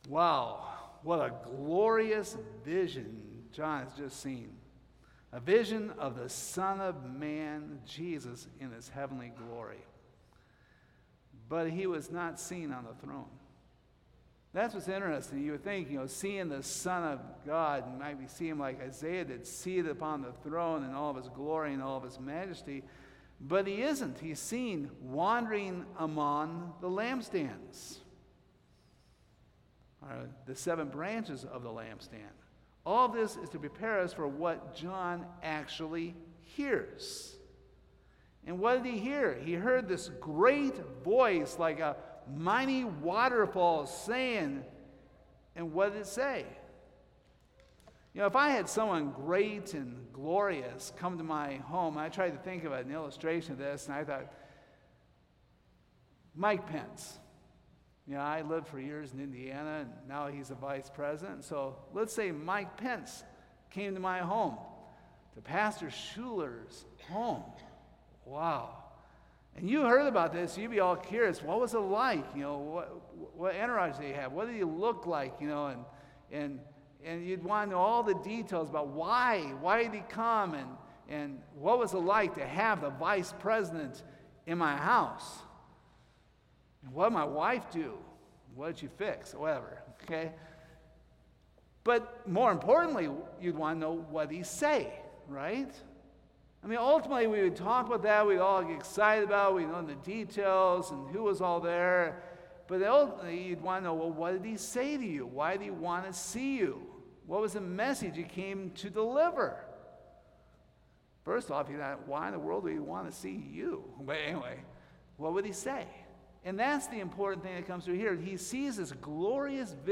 Revelation 1:17-19 Service Type: Sunday Morning What is the first thing Jesus offered to John?